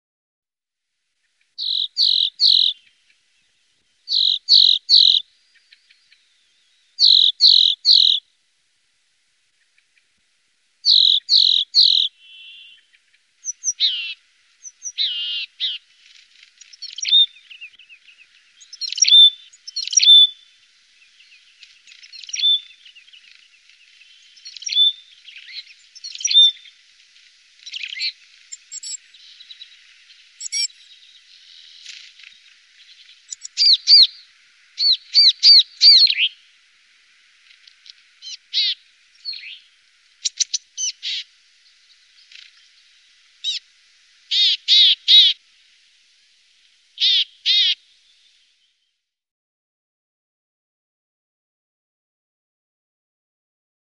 Lappmes / Siberian Tit Poecile cinctus Läte / Sound Du är här > Fåglar / Birds > Lappmes / Siberian Tit Galleri med utvalda fågelbilder / Favourites Norge, mars 2025.